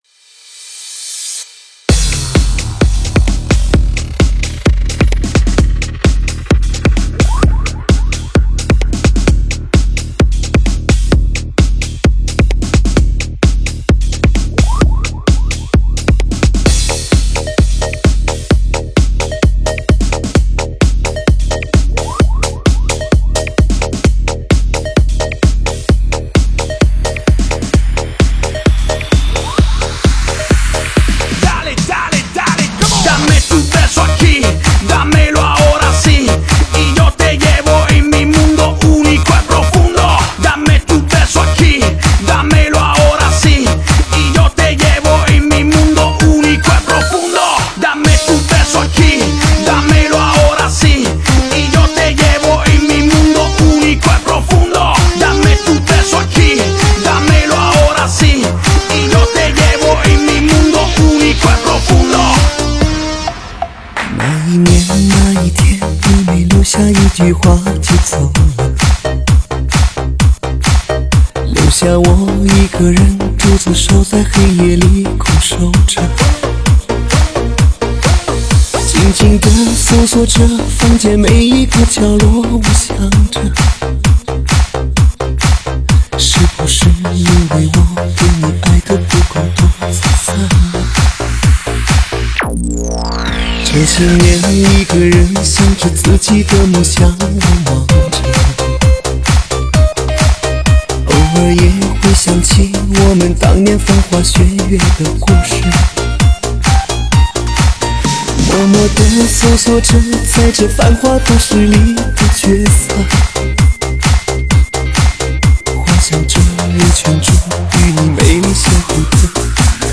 栏目：中文舞曲